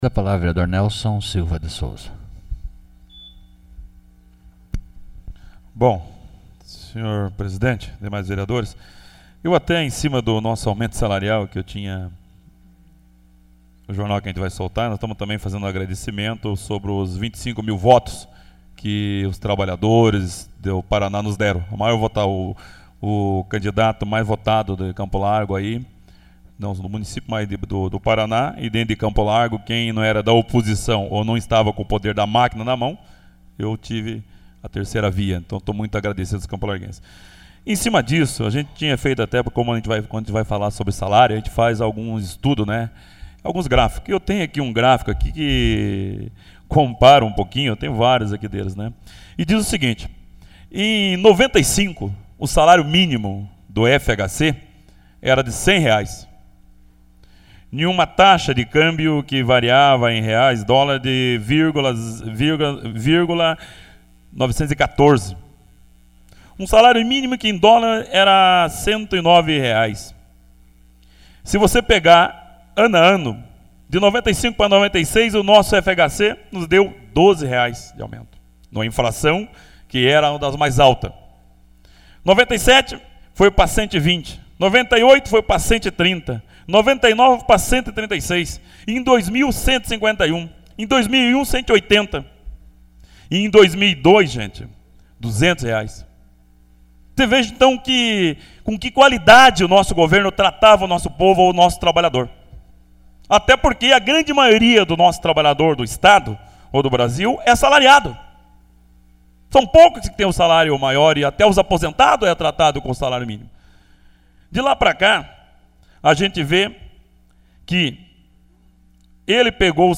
Pronunciamento pessoal.